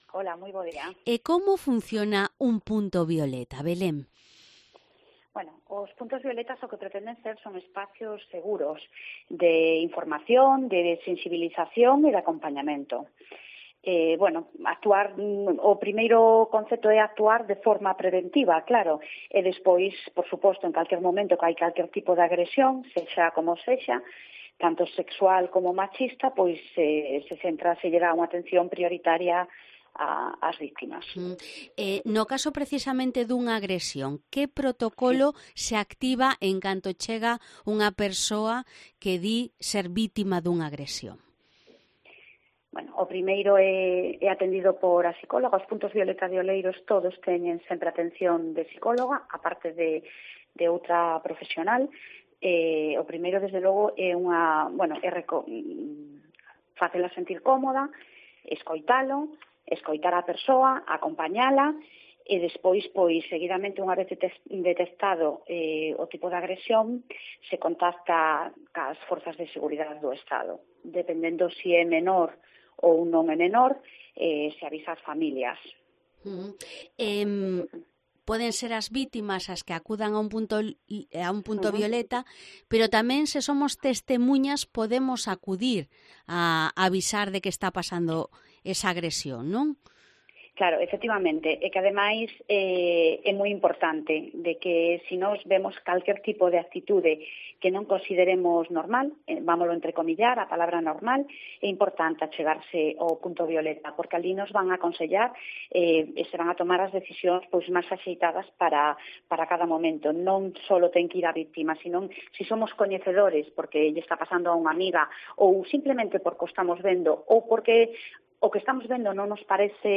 Entrevista a Belén Taboada, la concejala de Igualdade de Oleiros, sobre los puntos Violeta